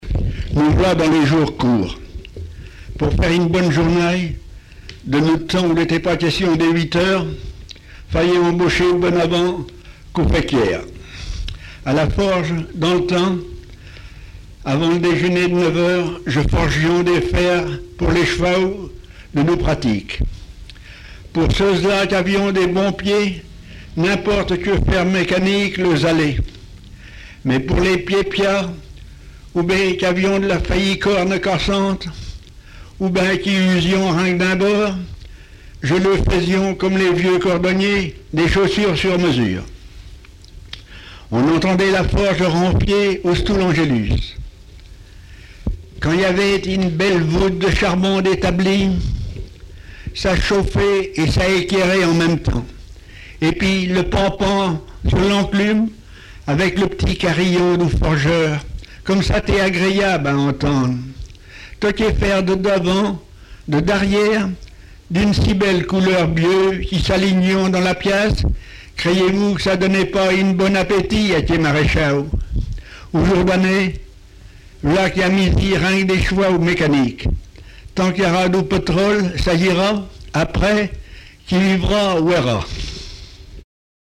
Informateur(s) lecteur pays de Retz inconnu
Patois local
Genre récit
textes en patois et explications sur la prononciation